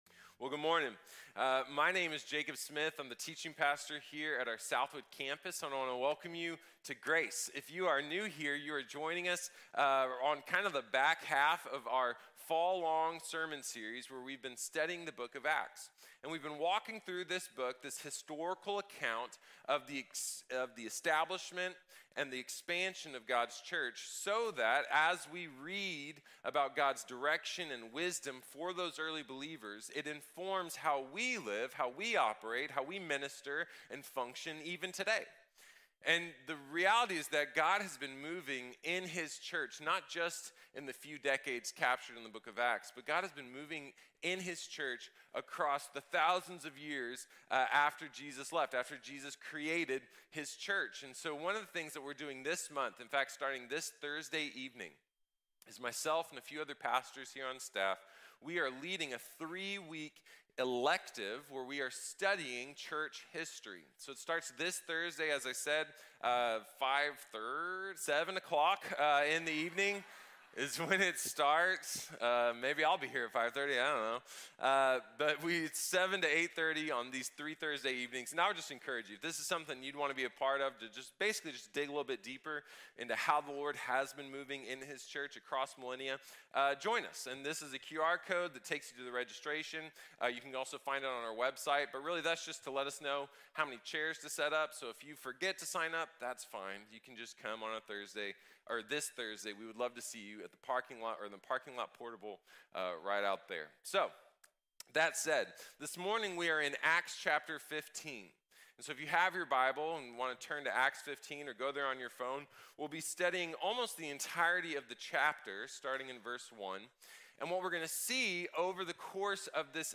La gracia sobre la ley | Sermón | Iglesia Bíblica de la Gracia